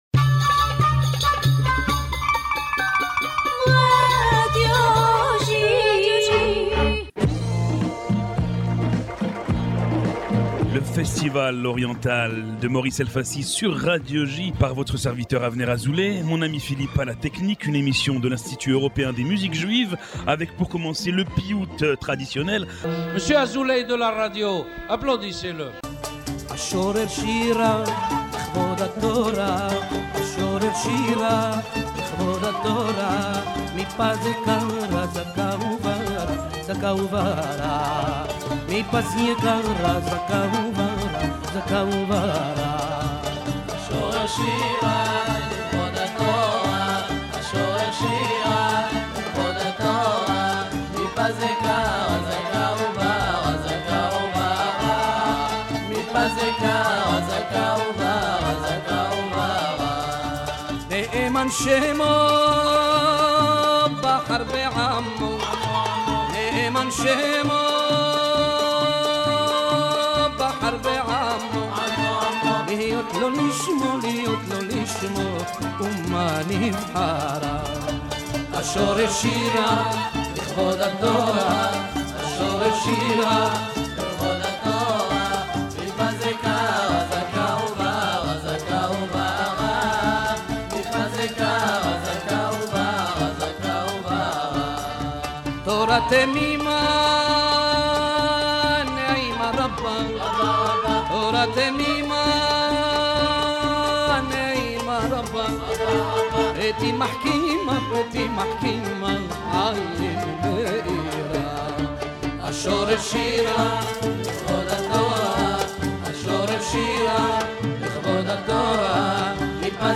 Broadcasted every Monday on Radio J (94.8 FM), « The Oriental festival » is a radio program from the European Institute of Jewish Music entirely dedicated to Eastern Music.